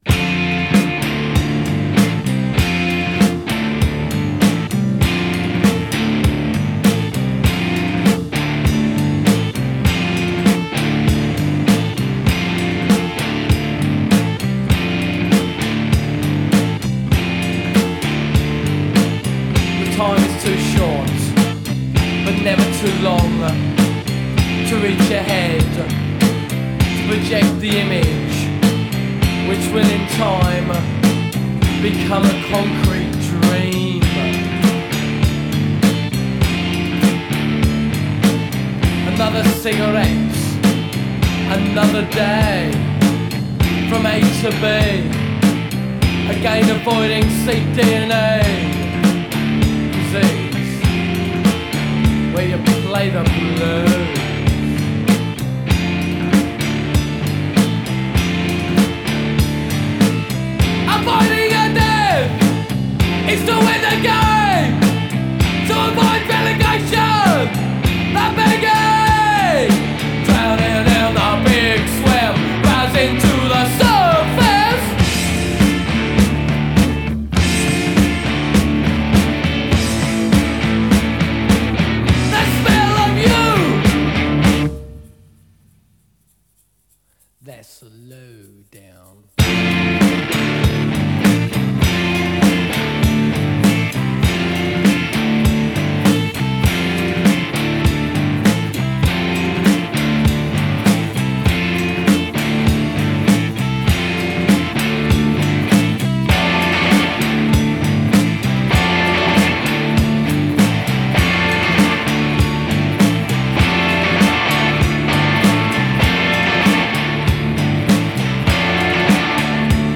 Two chords and the truth.
Brutal and minimalist.